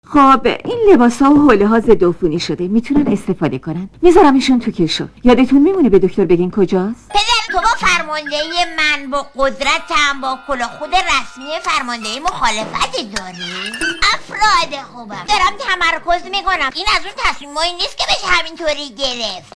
صوت | متخصص صدای کودکان
او در دوبلهٔ فیلم‌ها و مجموعه‌های تلویزیونی بیشتر صداپیشگی شخصیت‌های کم سن و سال، به‌خصوص پسر بچه‌ های شیطان و دختران جوان را انجام داده‌است.